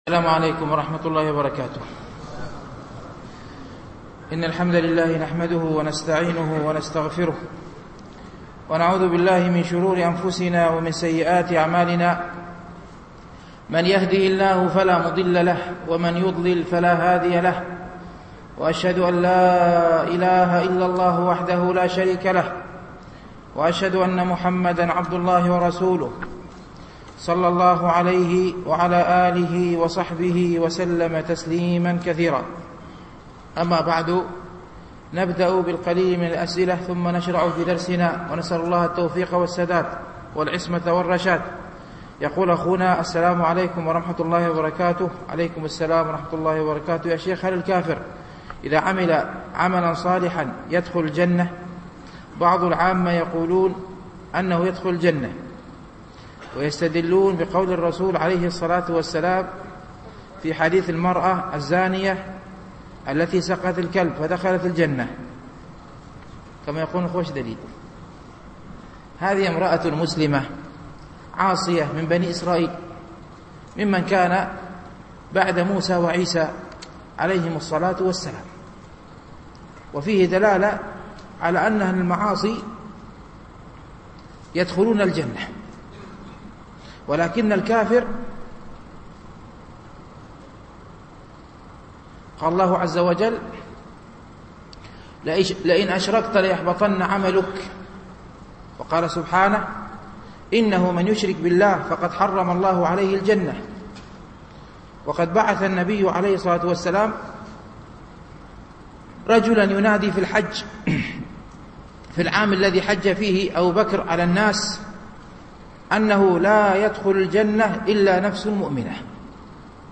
شرح رياض الصالحين - الدرس الثالث والعشرون بعد المئة